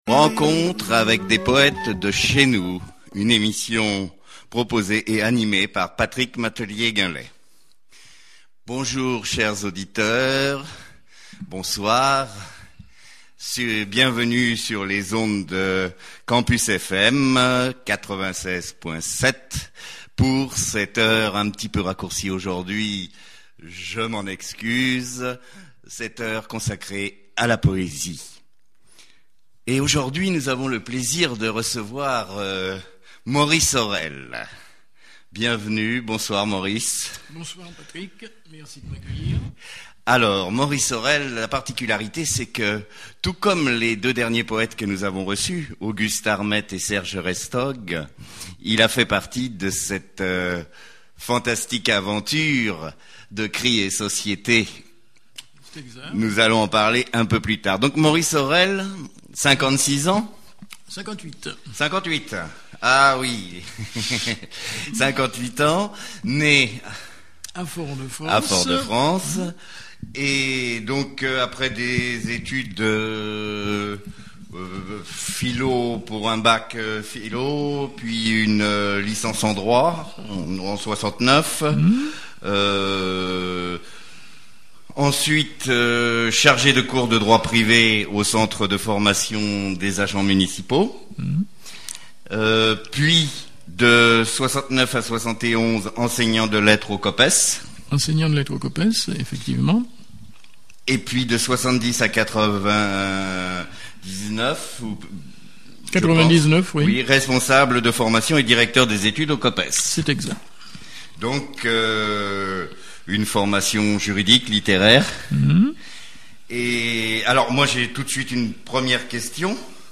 Émission radiophonique